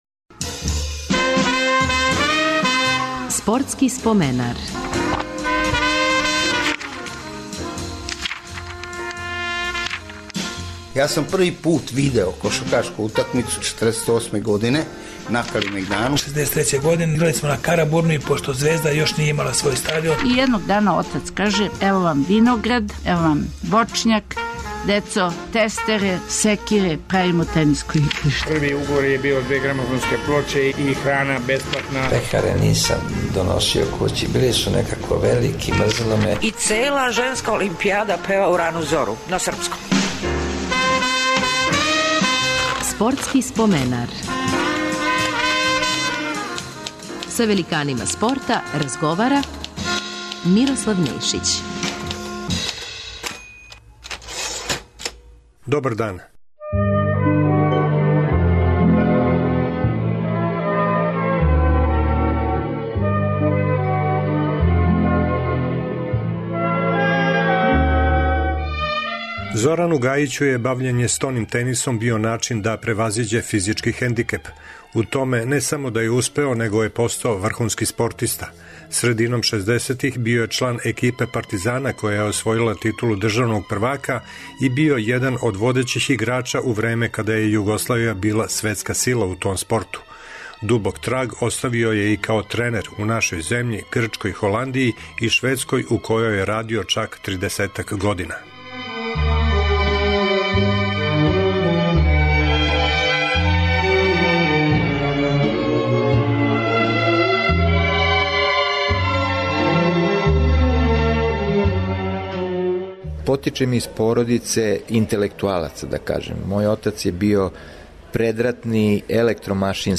Гост ће нам бити стонотенисер